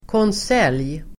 Ladda ner uttalet
konselj substantiv, cabinet meeting Uttal: [kåns'el:j] Böjningar: konseljen, konseljer Definition: regeringssammanträde cabinet meeting substantiv, konselj , kabinettssammanträde , regeringssammanträde